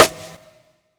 RIMSHOTTTD.wav